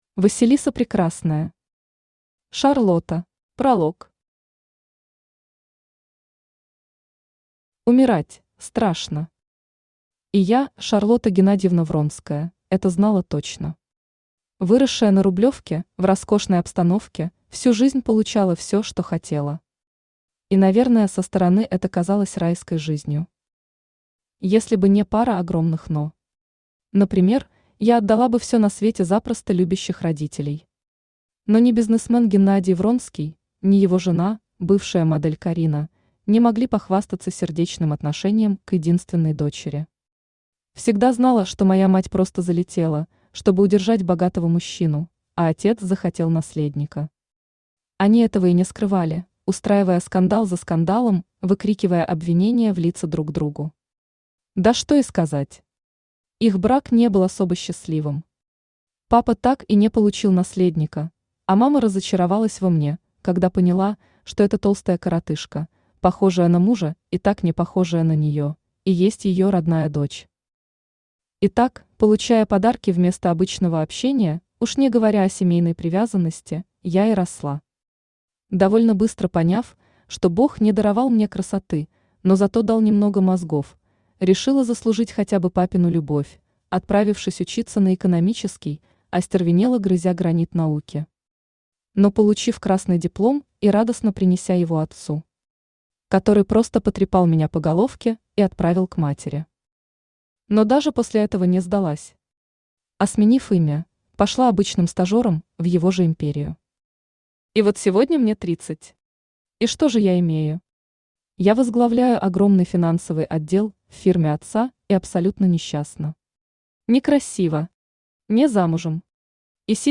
Аудиокнига Шарлотта | Библиотека аудиокниг
Aудиокнига Шарлотта Автор Василиса Прекрасная Читает аудиокнигу Авточтец ЛитРес.